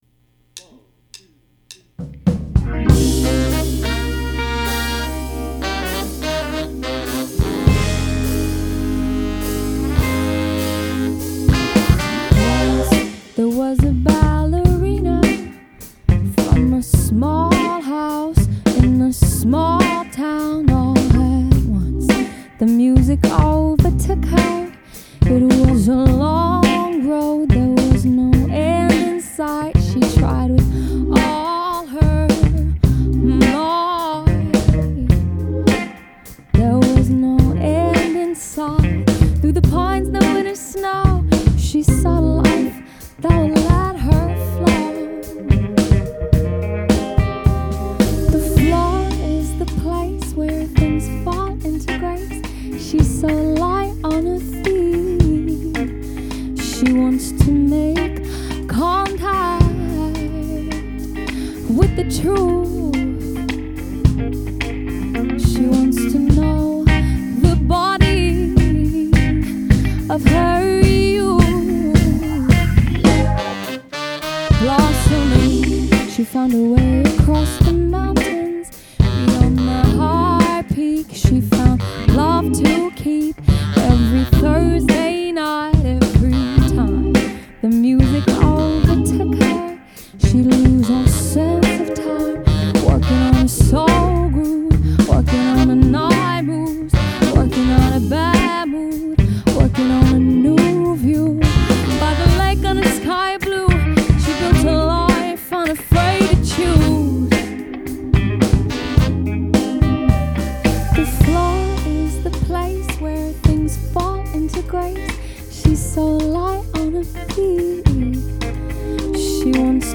Wie gesagt, das ist ein Roughmix ohne FX (nur Comp/Limiter auf den Vox), da soll und kann jetzt kein Hammer-Sound rauskommen, nur das, was mit Ozone bei diesem Rohmix möglich ist.